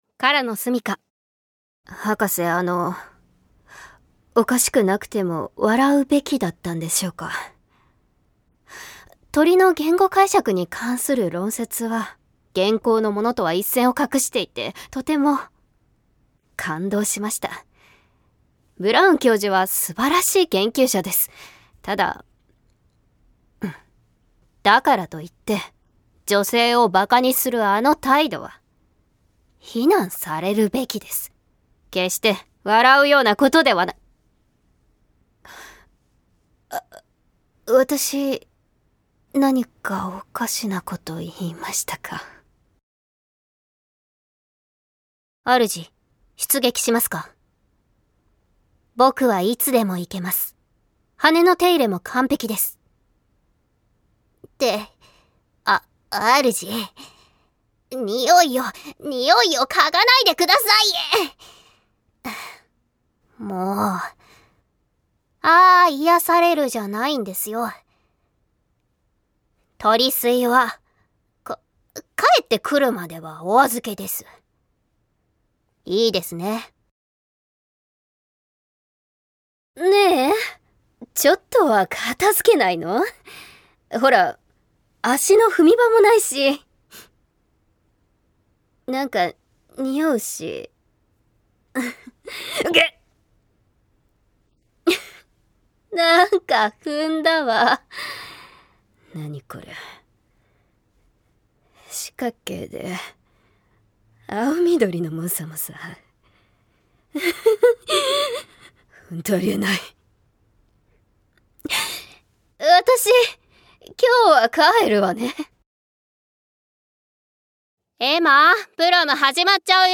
◆台詞